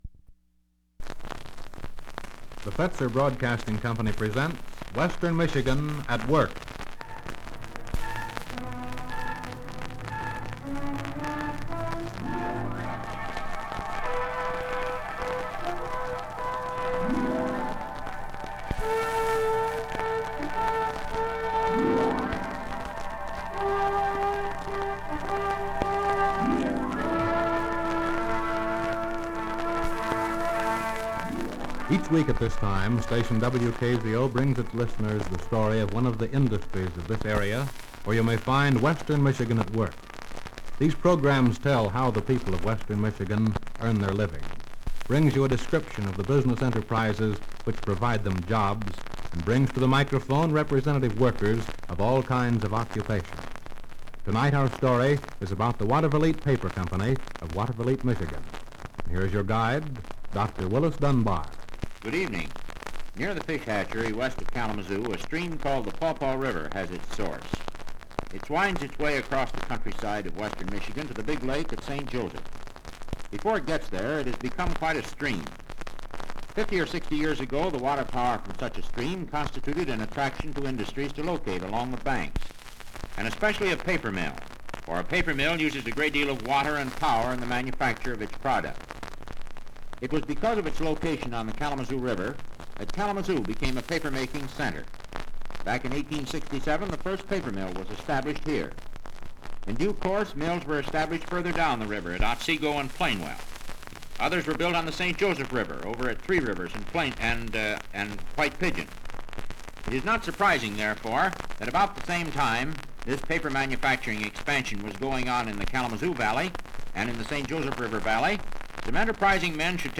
Interviews Radio programs